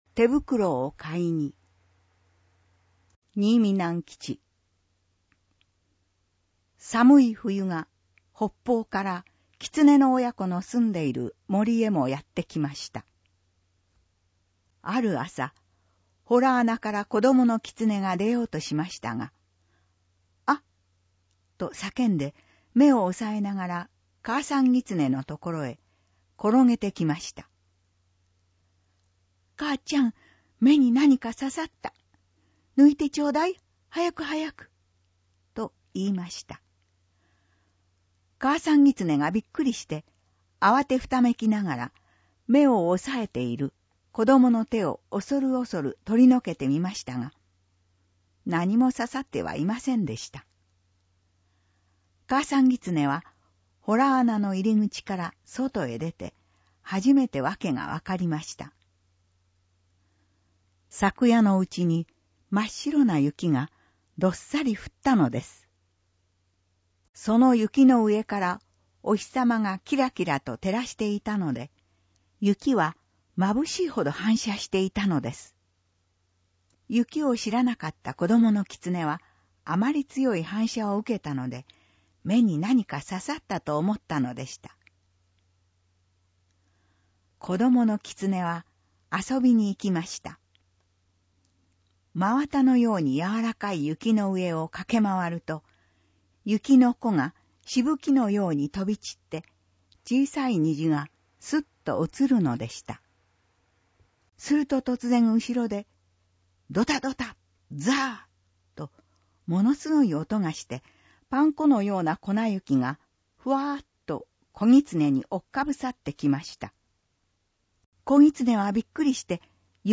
ぬり絵①（PDF・999KB） ぬり絵②（PDF・1342KB） 読み聞かせ お家でも楽しめるよう、音訳ボランティアさんによる読み聞かせをネット配信します！